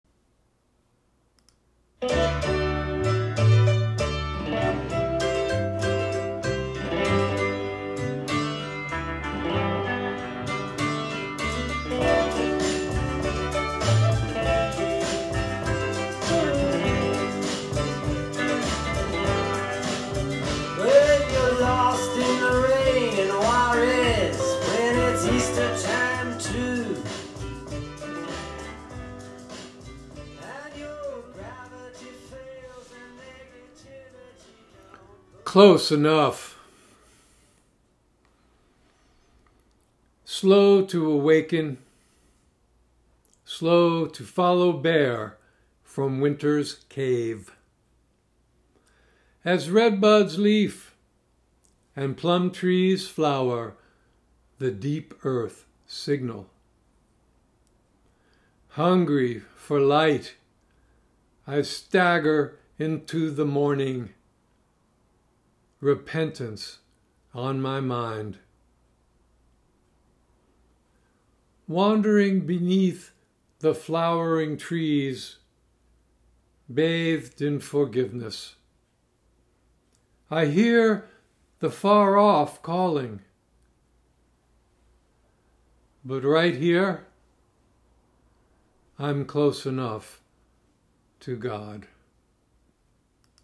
Reading of “Close Enough” with intro music by Bob Dylan